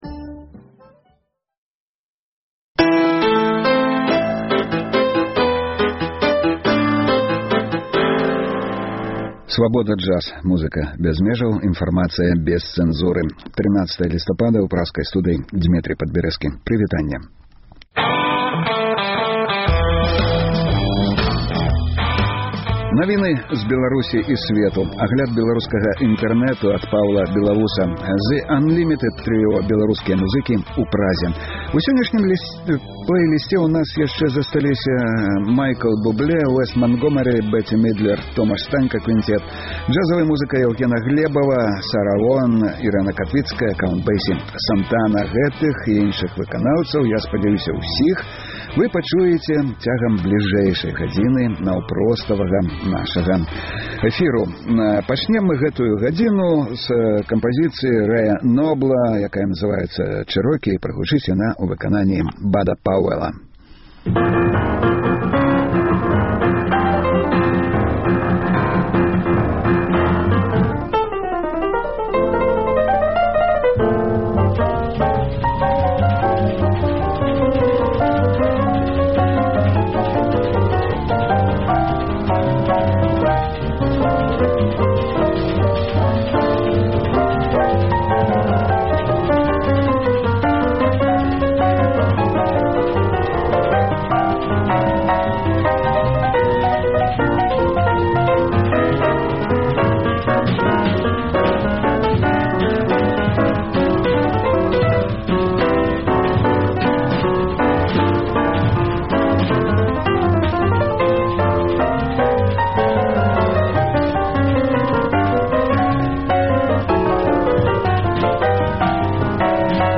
Слухайце ад 12:00 да 14:00 жывы эфір Свабоды!